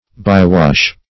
Search Result for " by-wash" : The Collaborative International Dictionary of English v.0.48: By-wash \By"-wash`\, n. The outlet from a dam or reservoir; also, a cut to divert the flow of water.